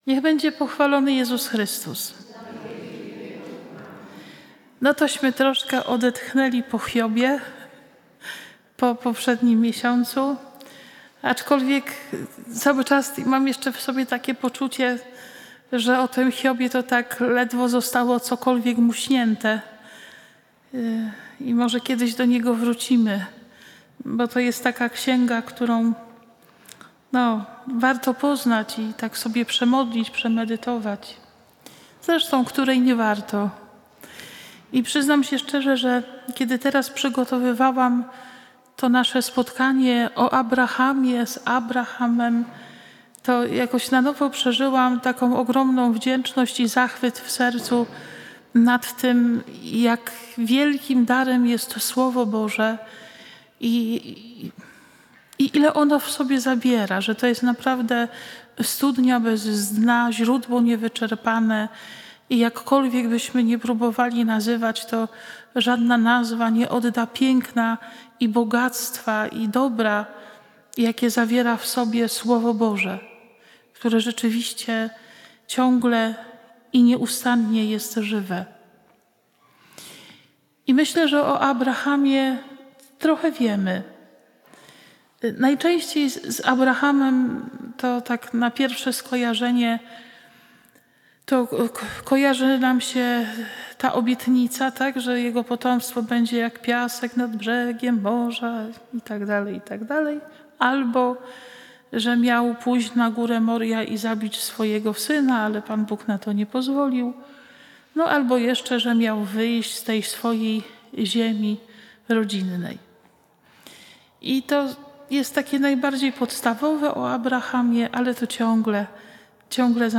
konferencja